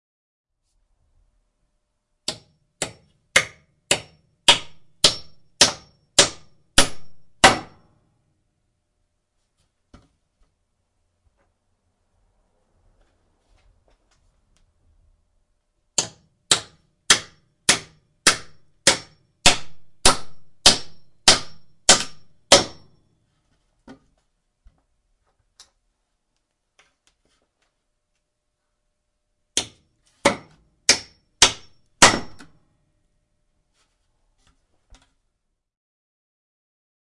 建筑声音 敲打钉子
描述：锤击钉子
标签： 锤击 建筑 指甲
声道立体声